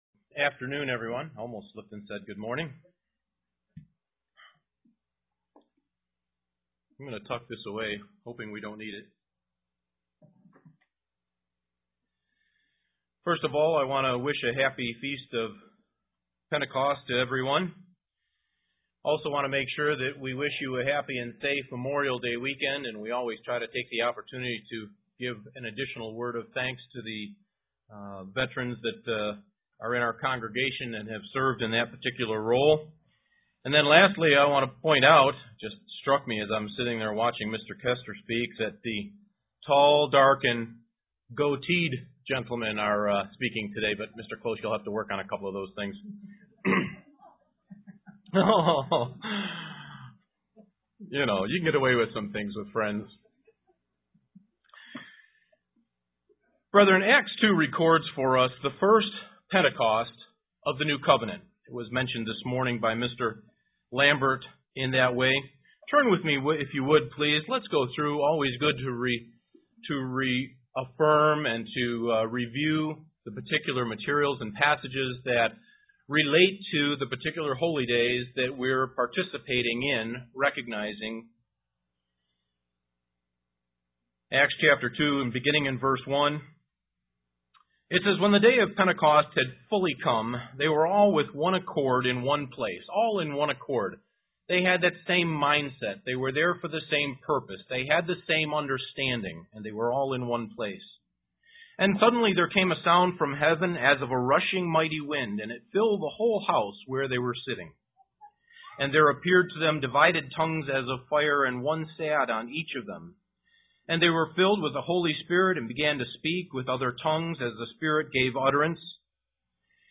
Given in Elmira, NY
Print The Church and Pentecost UCG Sermon Studying the bible?